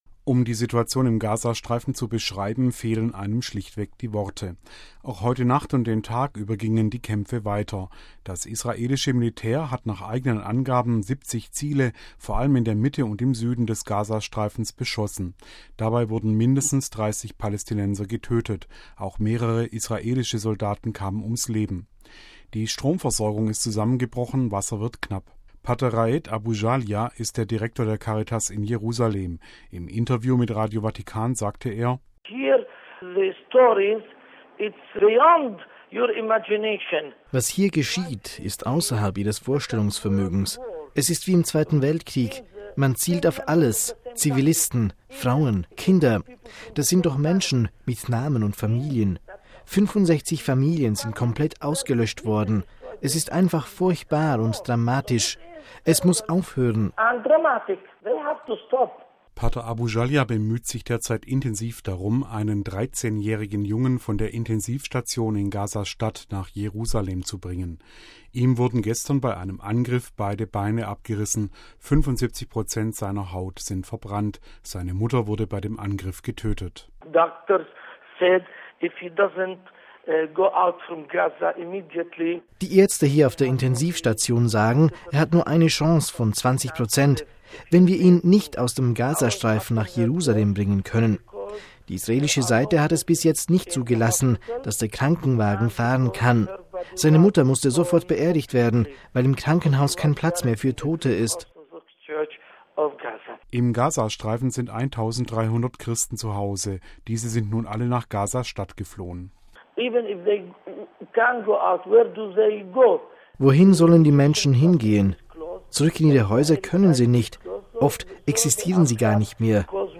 Im Interview mit Radio Vatikan sagte er: